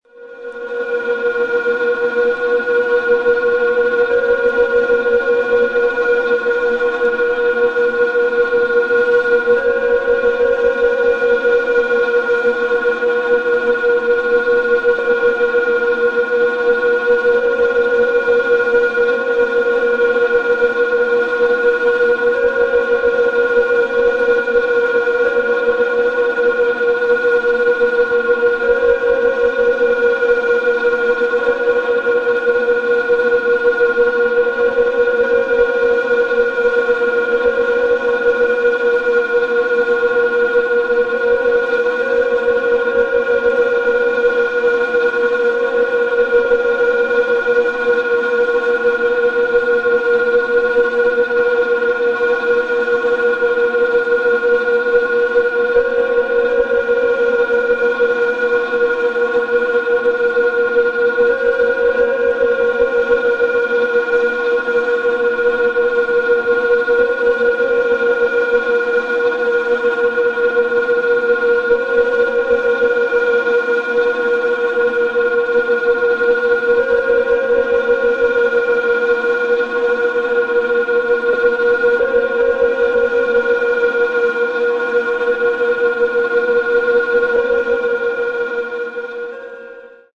トレモロの弦とフルートの音色がどこか和の雰囲気も感じさせる